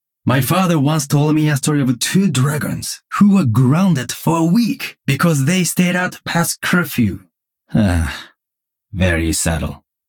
These voice lines are all from Heroes of the storm and I think they’re too good to not be in the game…plz jeff
GenjiBasePissed10.ogg